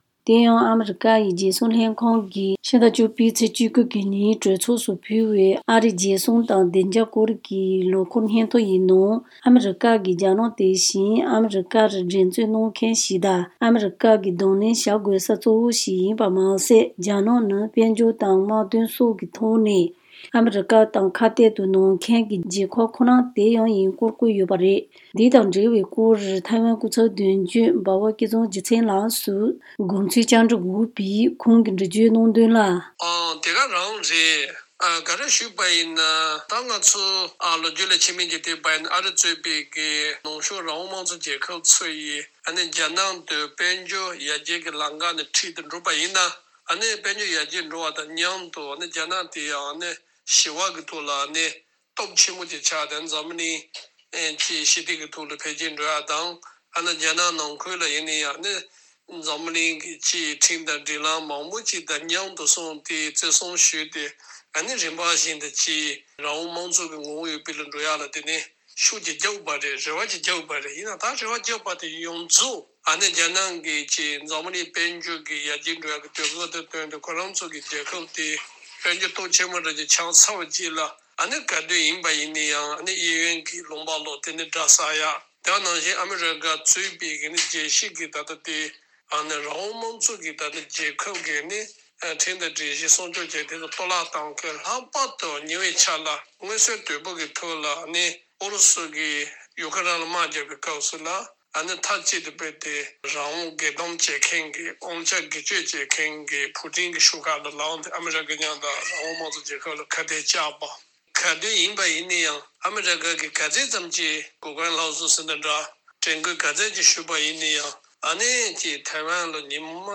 བཅའ་འདྲི་དང་གནས་ཚུལ་ཕྱོགས་བསྡུས་བྱས་པར་ཉན་རོགས་ཞུ།